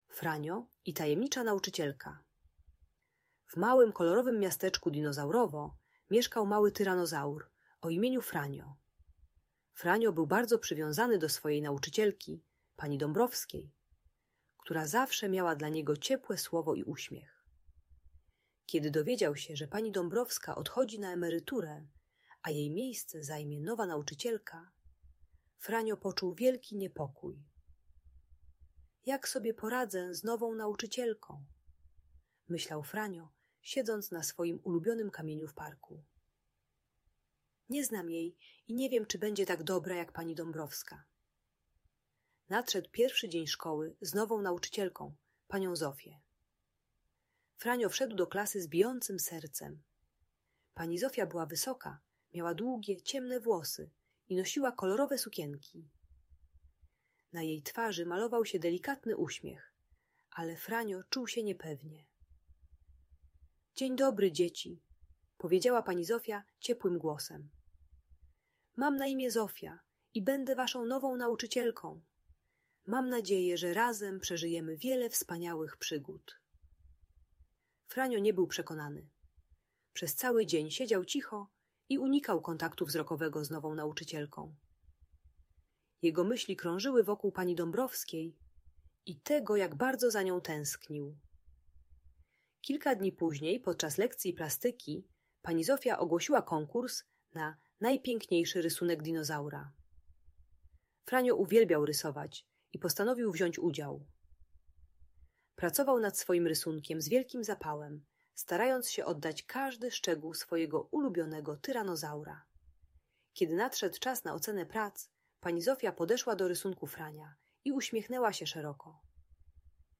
Historia Frania i tajemniczej nauczycielki - Audiobajka